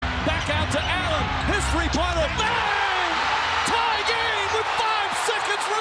ray-allens-amazing-game-tying-3-pointer-in-game-6-mp3cut.mp3